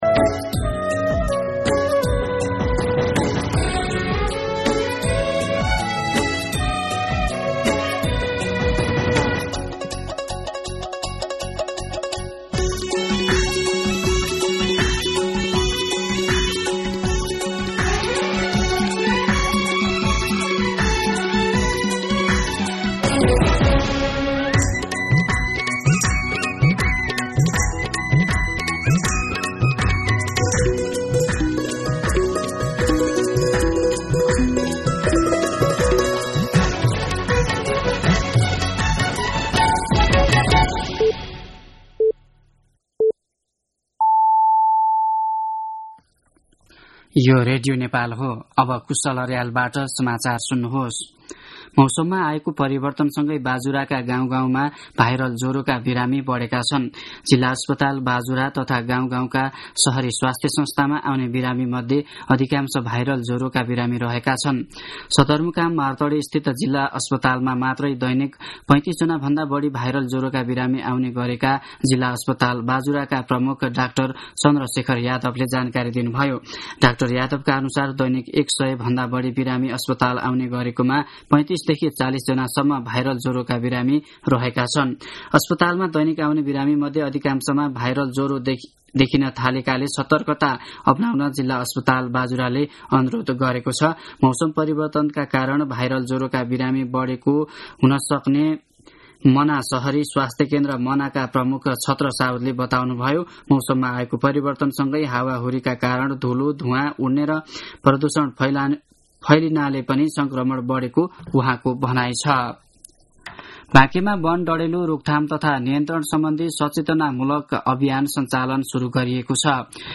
दिउँसो १ बजेको नेपाली समाचार : ९ चैत , २०८१